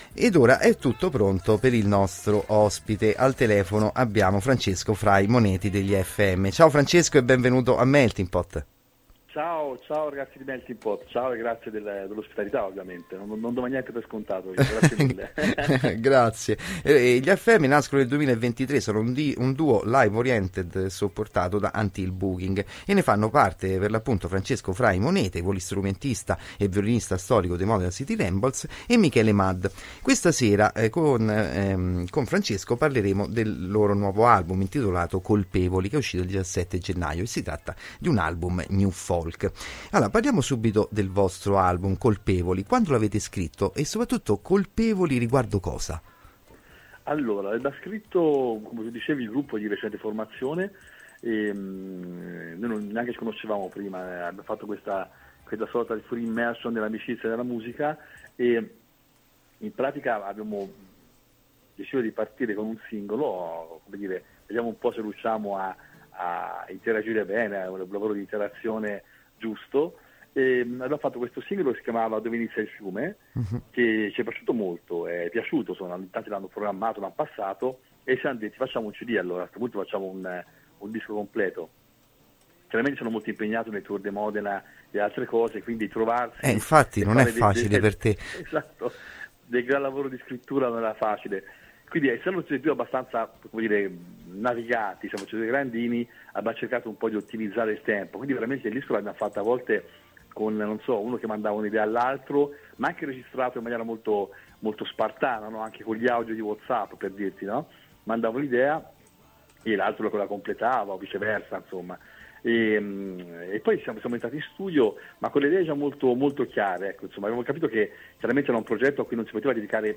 intervistare telefonicamente